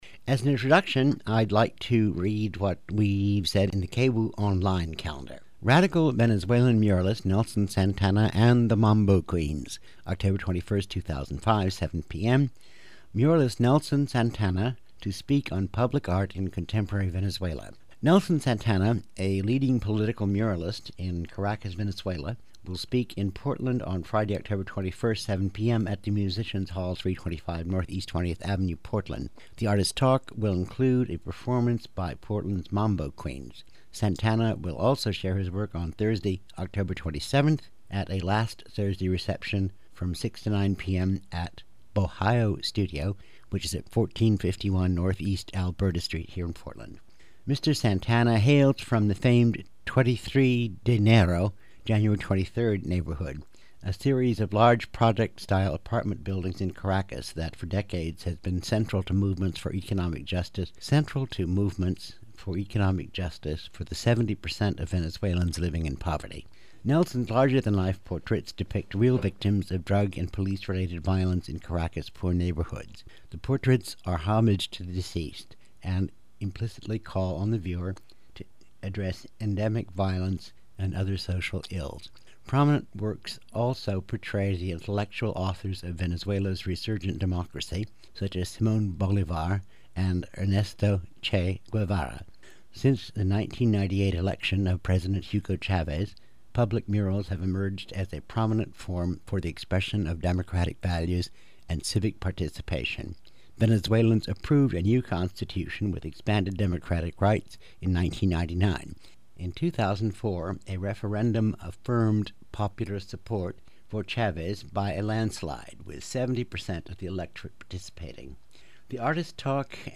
Location Recorded: Musicians Hall, Oortland Oregon